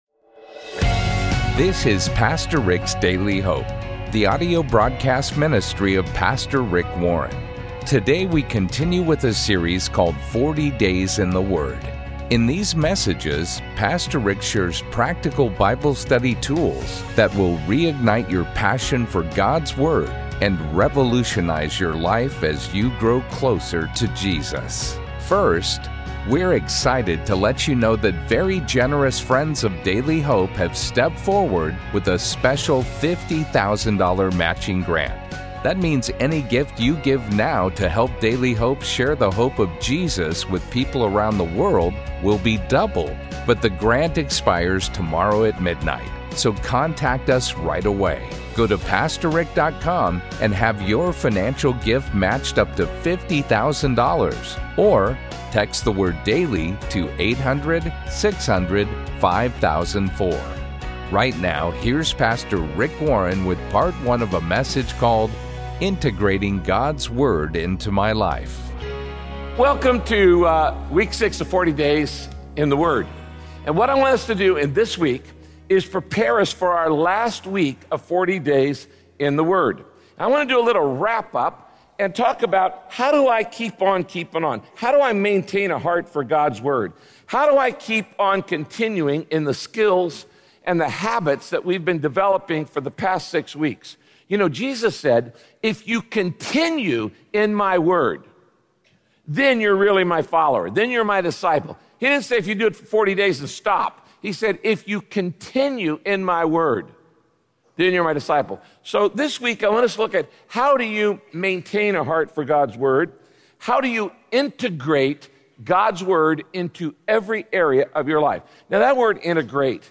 My Sentiment & Notes Integrating God’s Word Into My Life - Part 1 Podcast: Pastor Rick's Daily Hope Published On: Thu Jun 29 2023 Description: If you starve yourself of God’s Word, you won’t have much success in winning spiritual battles. In this broadcast, Pastor Rick teaches how to feed yourself on the Word of God—the only thing that will feed your soul.